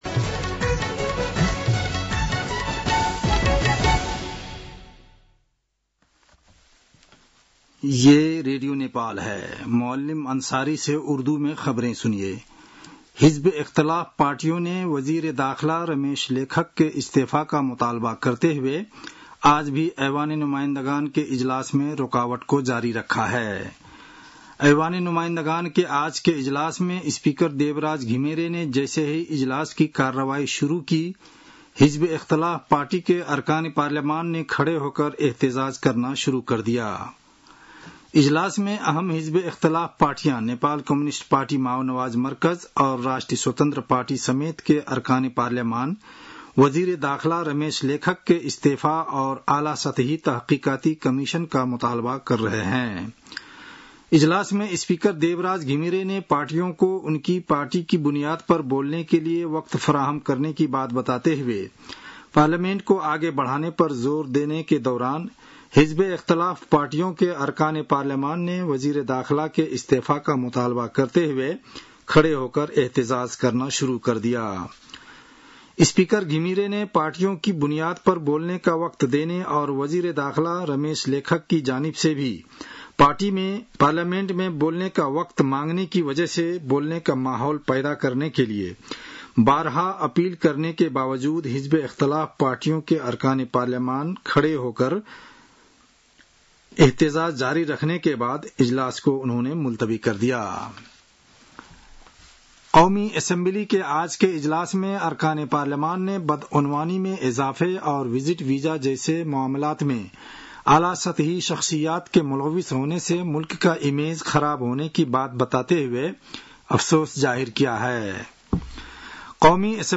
उर्दु भाषामा समाचार : १४ जेठ , २०८२